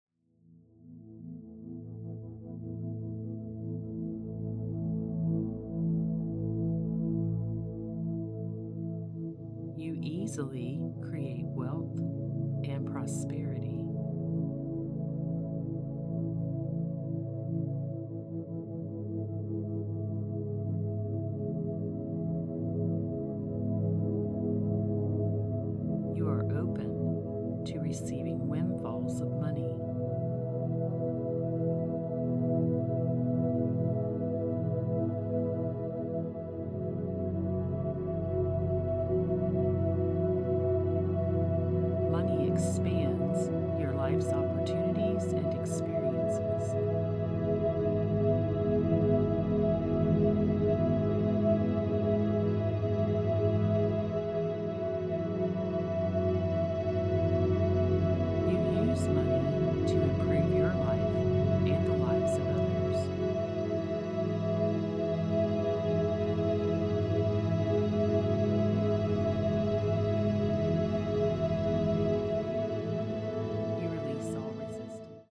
These “subliminal money affirmations” are combined with a theta wave track to program your mind to attract prosperity, wealth and a life of luxury while you sleep.